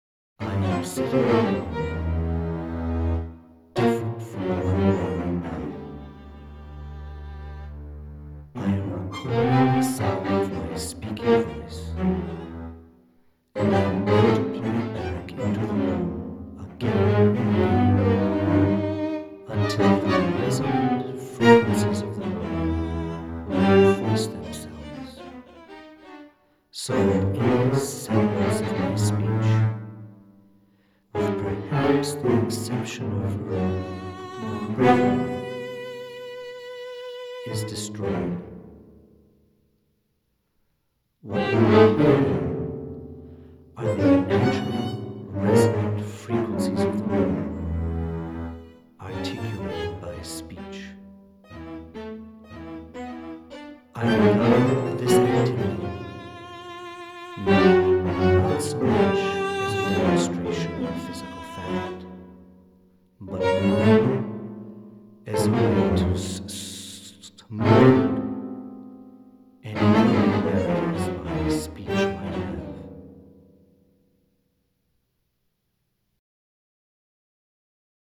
Pour quatuor de cordes, harpe, piano
Conçues à l’origine comme des tests techniques, ces transcriptions impossibles étudient le passage entre un enregistrement audio & une orchestration midi.